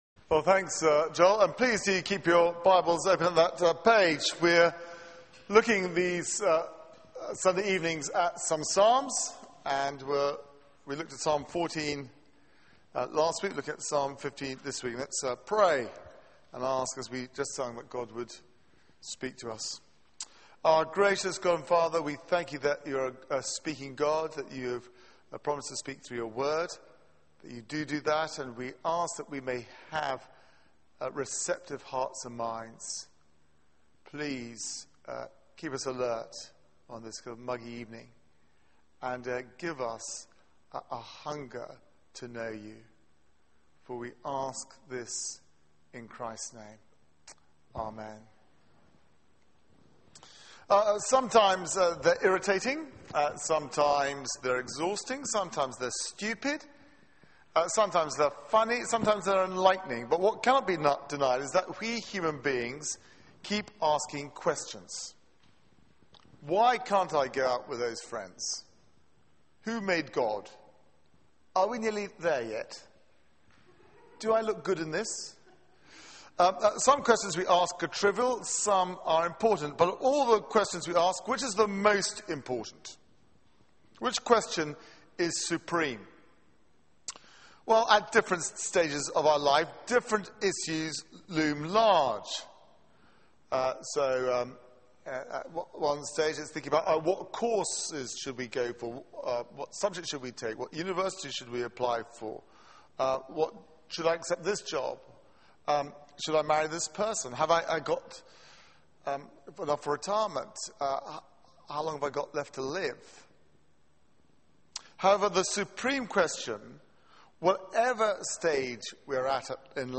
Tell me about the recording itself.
Media for 6:30pm Service on Sun 19th Aug 2012 18:30 Speaker